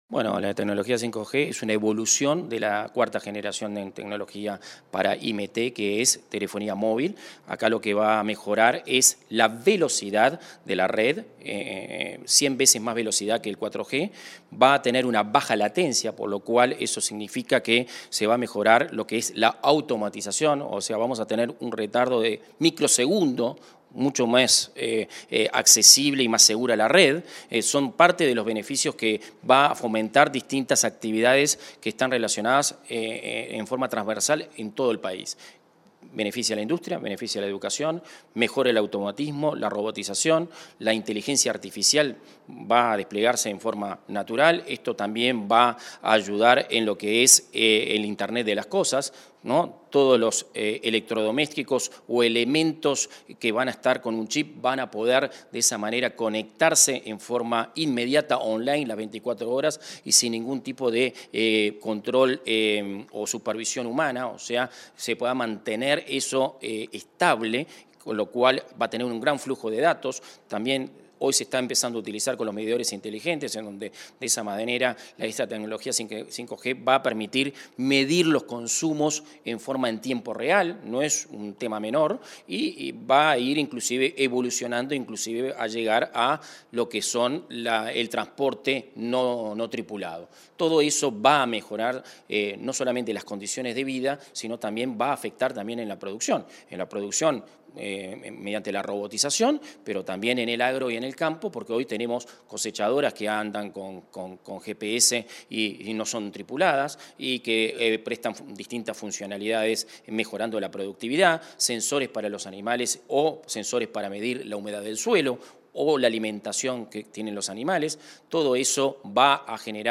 Entrevista al director nacional de Telecomunicaciones, Guzmán Acosta y Lara